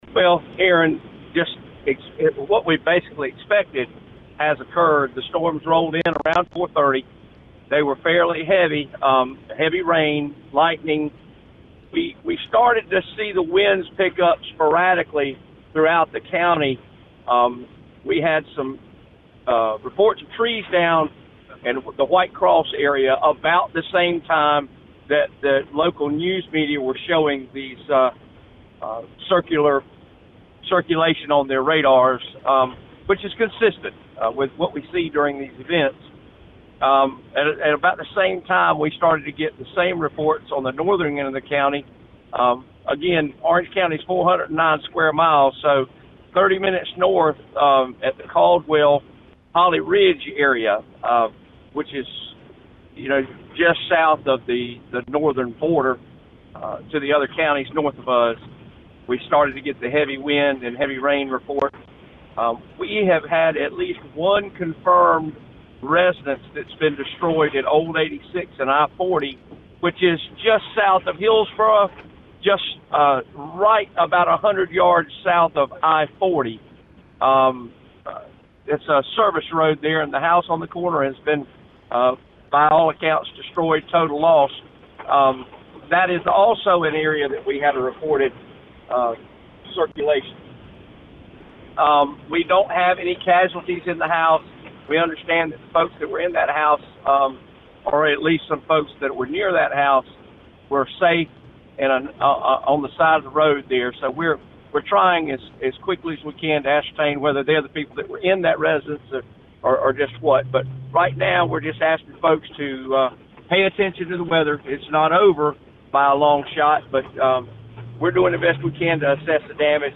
Orange County Sheriff Charles Blackwood: